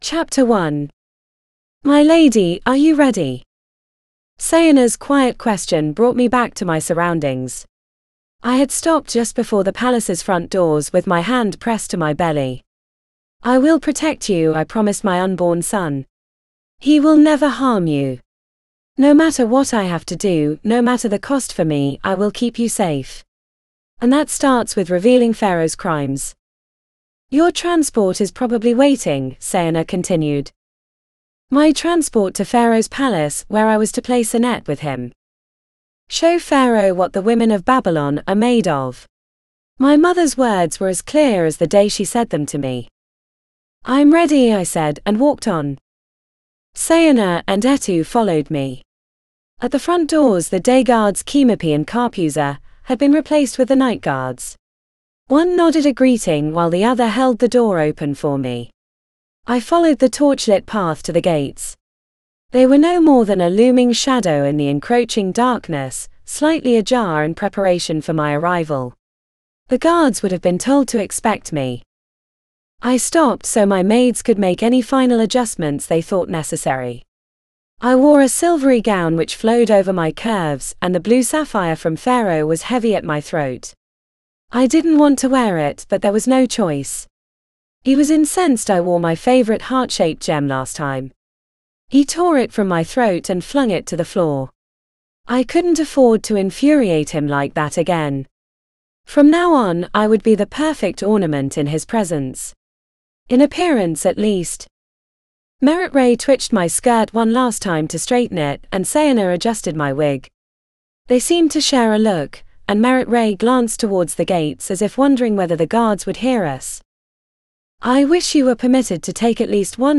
Secrets of Pharaoh Audiobook
Narrator: This audiobook is digitally narrated using the voice of Anya and produced by Google Play.